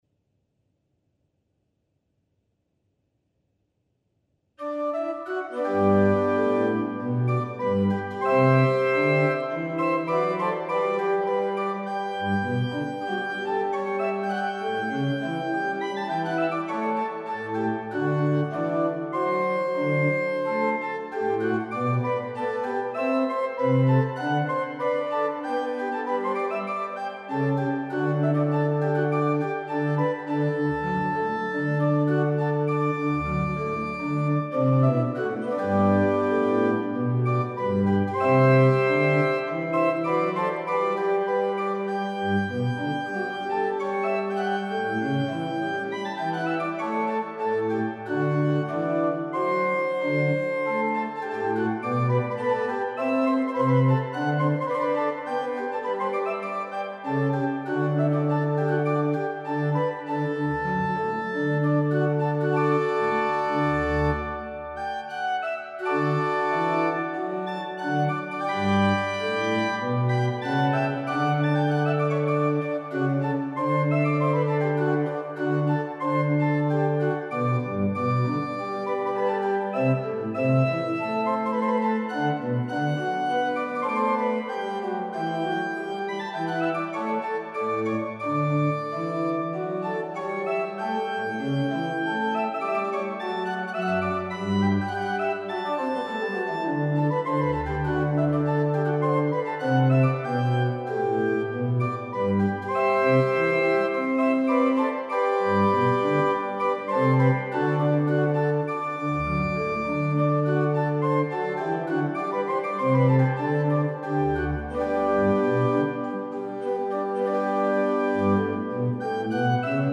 The keyboard partitas are equally attractive.